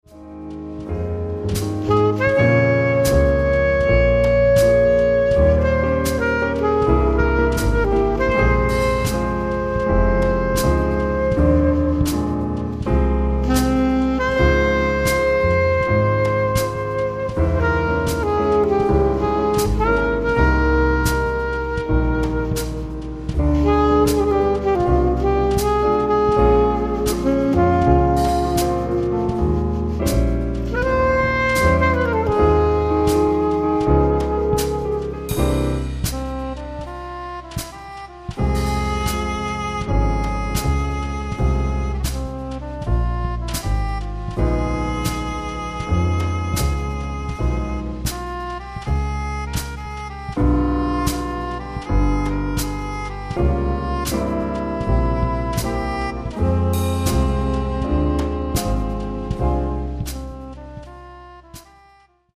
Nun ein Rätsel: Was ist in der folgenden Aufnahme MIDI, was real?